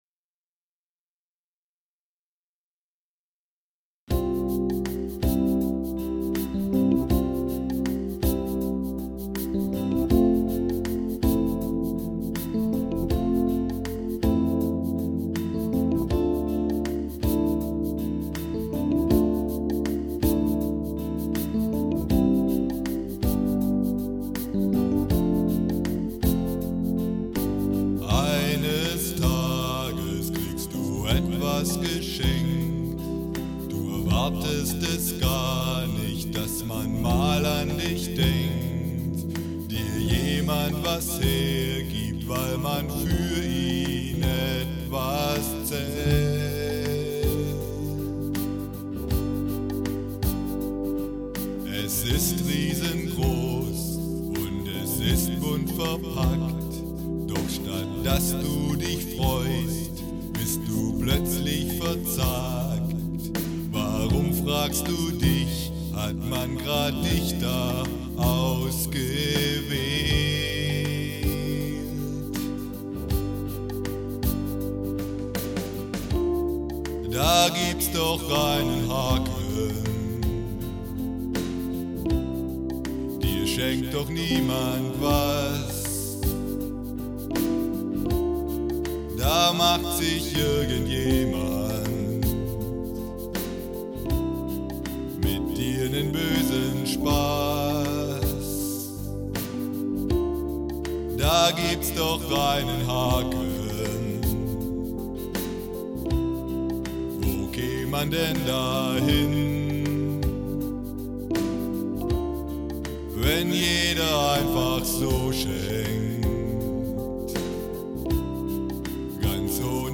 Musikalische Geschichten